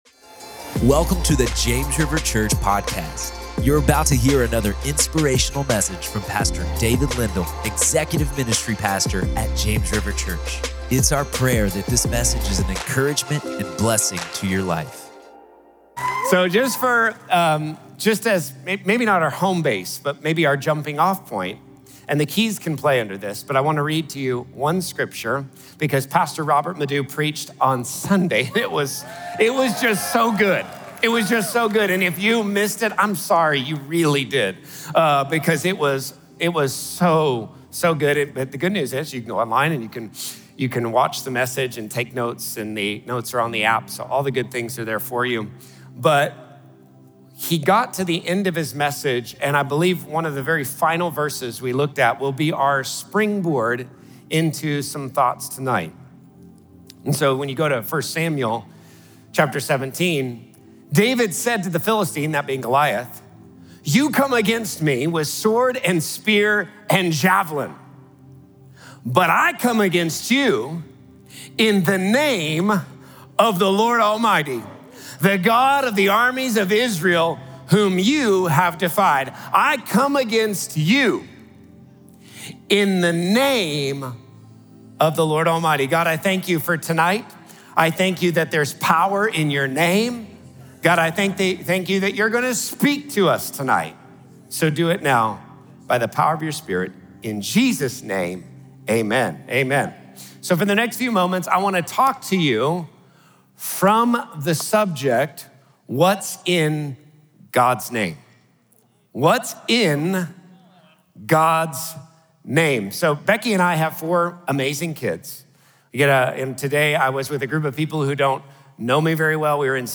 What's In God's Name? | Prayer Meeting